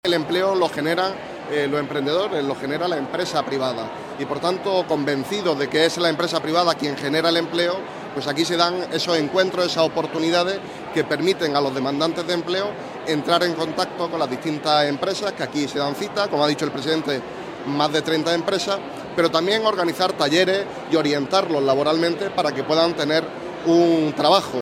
FERNANDO-GIMENEZ-VICEPRESIDENTE-SEGUNDO-DIPUTACION.mp3